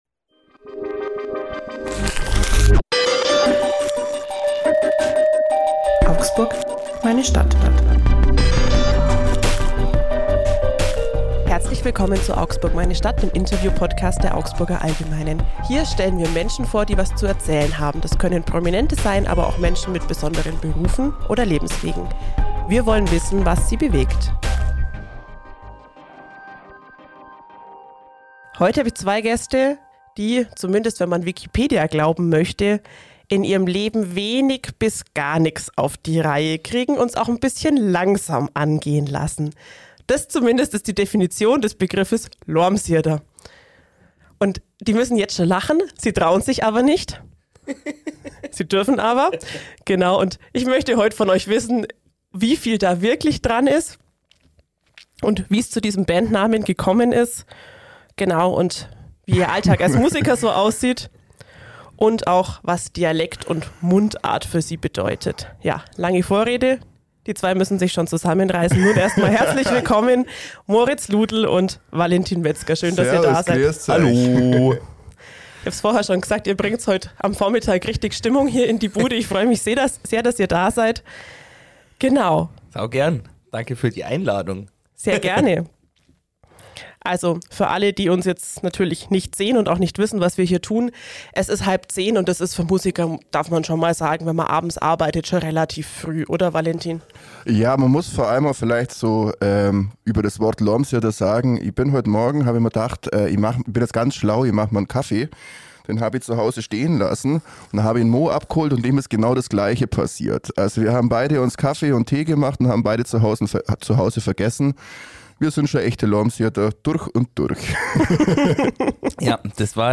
Im Podcast "Augsburg, meine Stadt" sprechen die beiden Frontmänner darüber, warum sie sich für Musik im Dialekt entschieden haben, was fränkischer Hochmoorgeist mit der Bandgründung zu tun hat und wie ihre Begeisterung für Musik geweckt wurde.